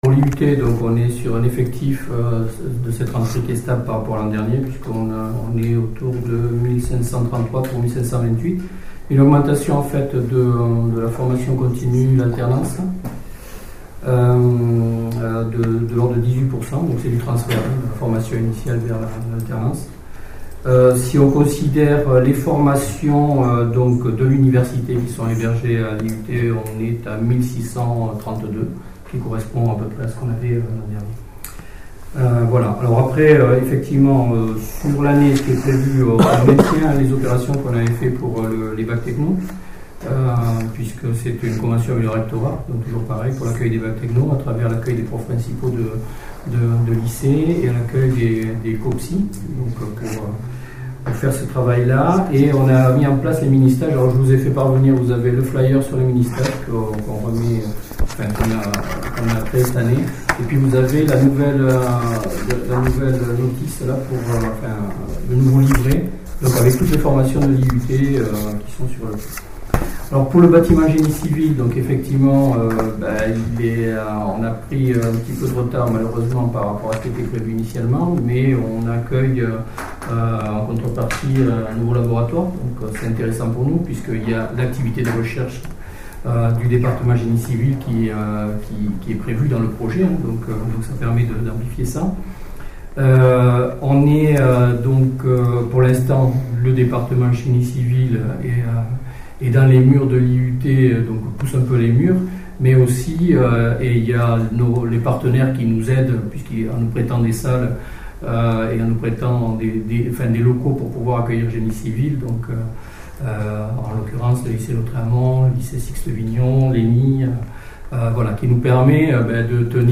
Chaque responsable d’établissement est ensuite intervenu.
Les interventions